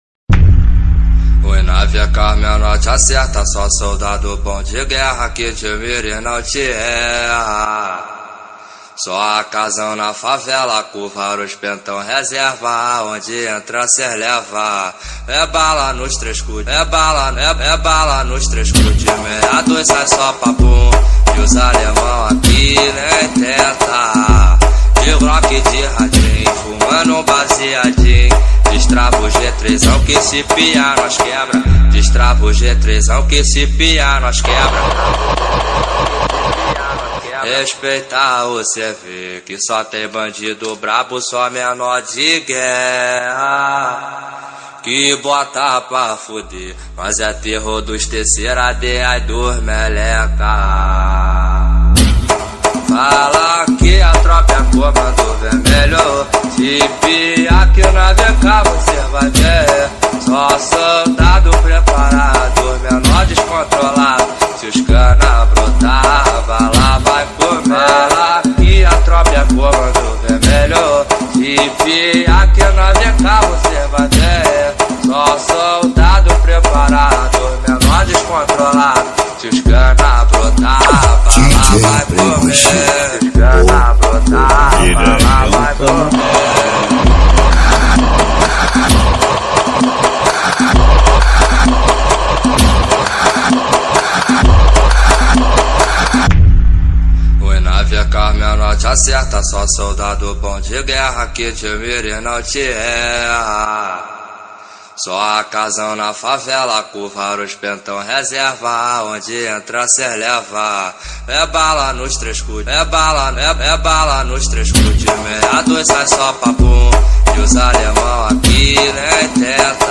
2025-04-17 19:55:29 Gênero: Funk Views